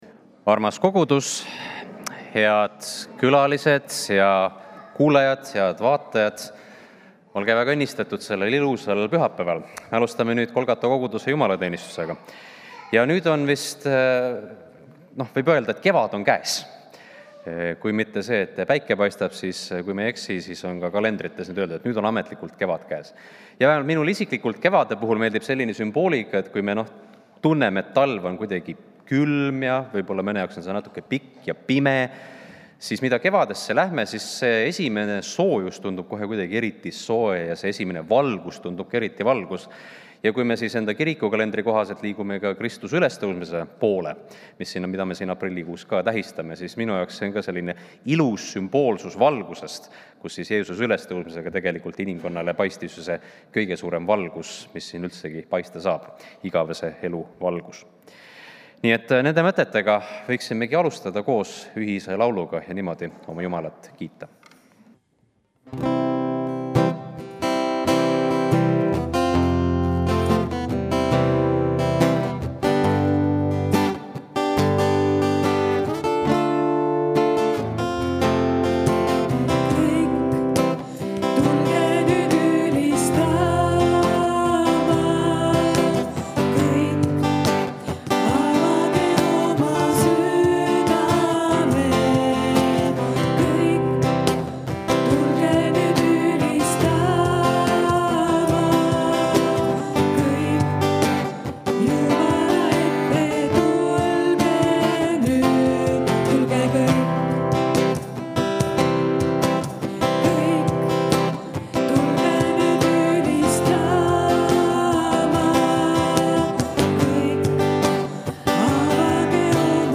Kõik jutlused